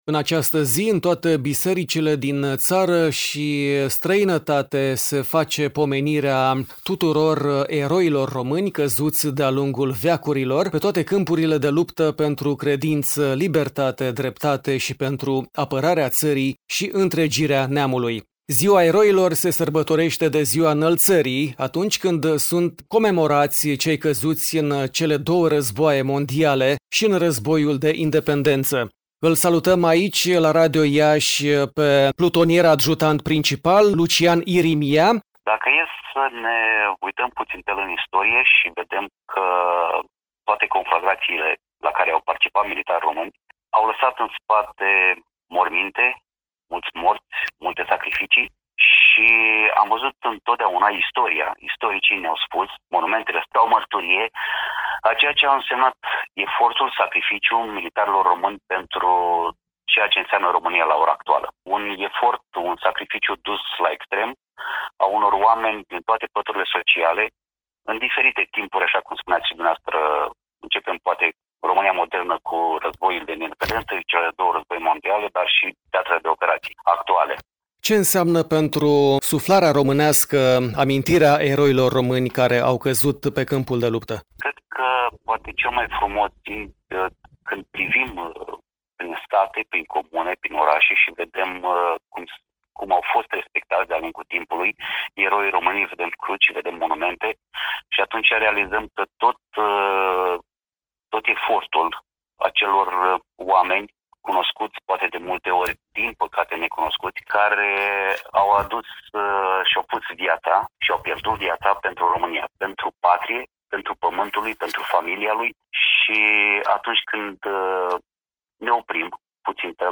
Un moment In memoriam în amintirea eroilor români căzuți pe fronturile apărării României , avem prin vocea